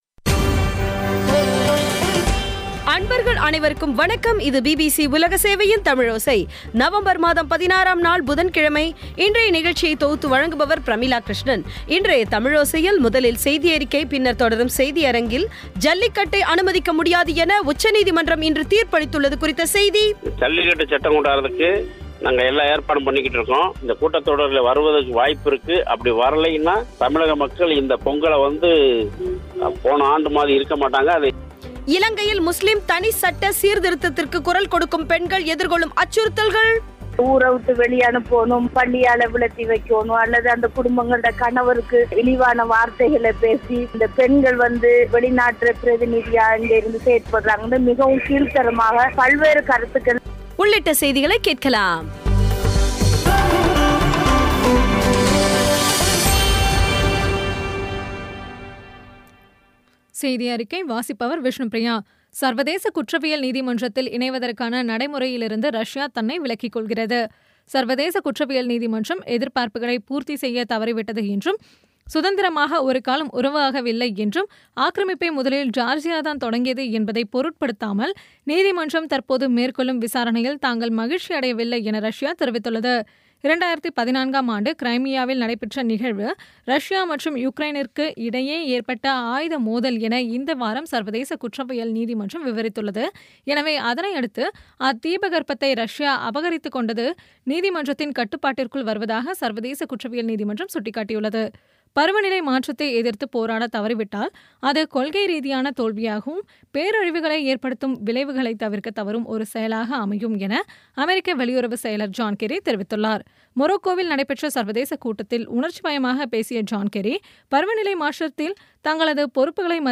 இன்றைய தமிழோசையில், முதலில் செய்தியறிக்கை, பின்னர் தொடரும் செய்தியரங்கத்தில் ஜல்லிக்கட்டை அனுமதிக்க முடியாது என உச்சநீதிமன்றம் இன்று தீர்ப்பளித்துள்ளது குறித்த செய்தி இலங்கையில் முஸ்லீம் தனி சட்ட சீர்திருத்தத்திற்கு குரல் கொடுக்கும் பெண்கள் எதிர்கொள்ளும் அச்சுறுத்தல்கள் பற்றிய செய்தி உள்ளிட்ட செய்திகளை கேட்கலாம்